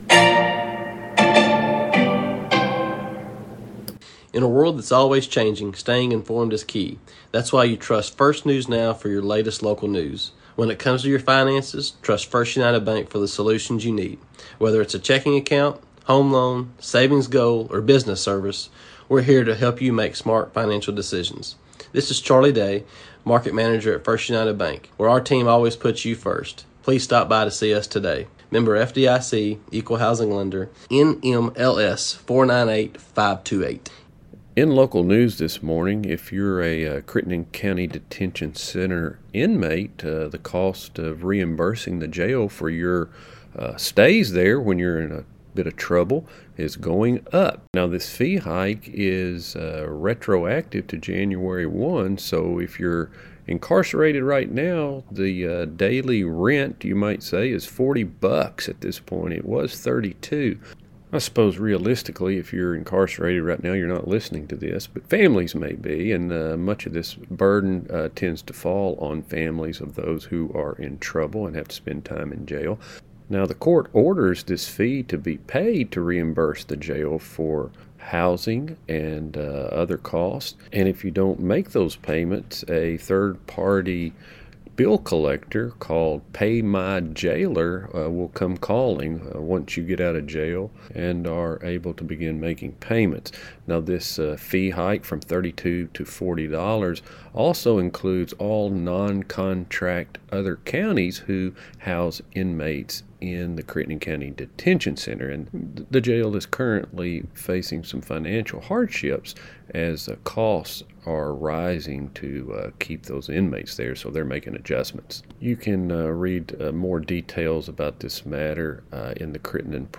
LISTEN NOW On Monday's NEWScast get recent obituaries, news and sports from around the area. Some highlights include: New Cell Towers, a robbery suspect in custody, a conversation with Sen. Jason Howell, Basketball Updates and even some Football News